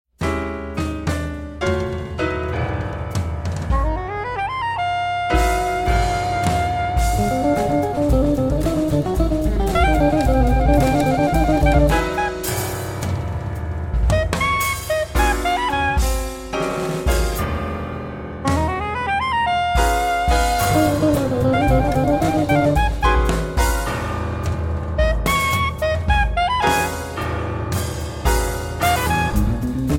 Guitar
Soprano Saxophone
Piano
Bass
Drums